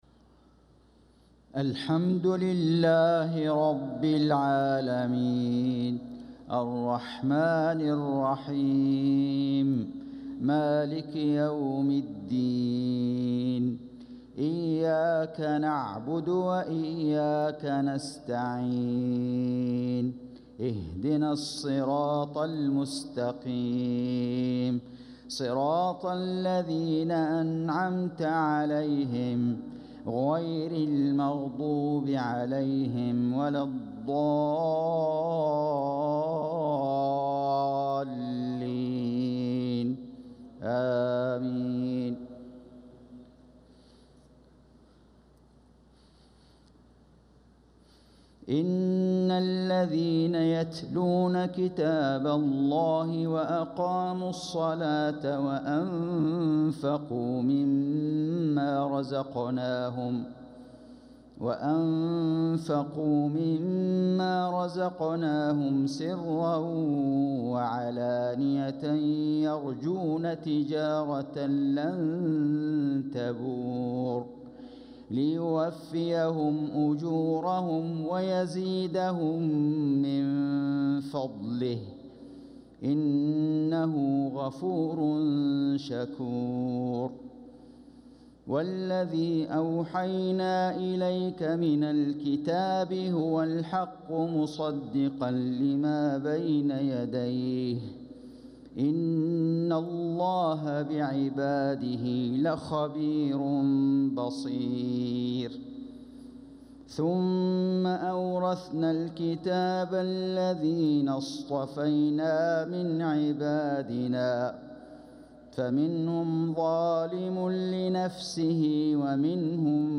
صلاة العشاء للقارئ فيصل غزاوي 7 صفر 1446 هـ
تِلَاوَات الْحَرَمَيْن .